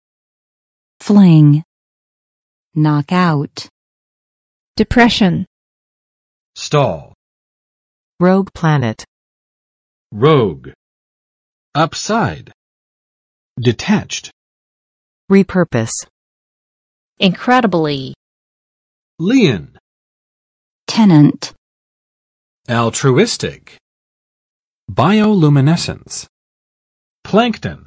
[flɪŋ] v.（用力地）扔，掷，抛，丢